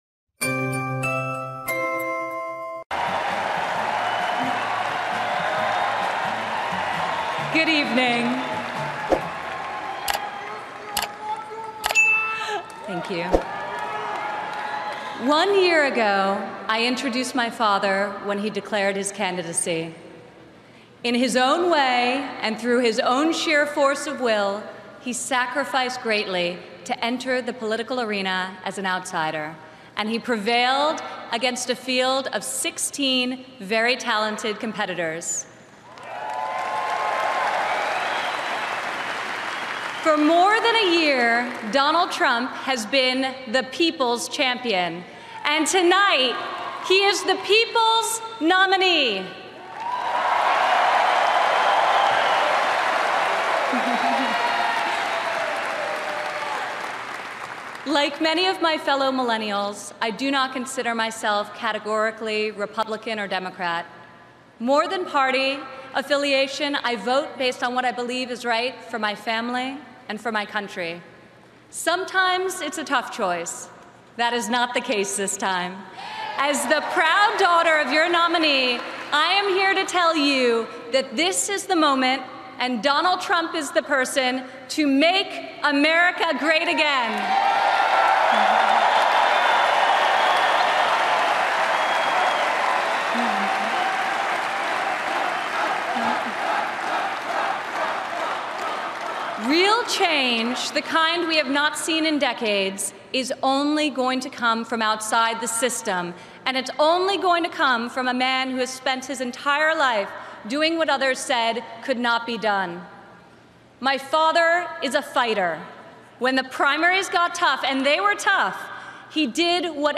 ► Speaker: Ivanka Trump ► Link gốc: • Ivanka Trump Full… ► Thông tin: Bài phát biểu đầy đủ của Ivanka Trump tại Hội nghị Đảng Cộng hòa 2016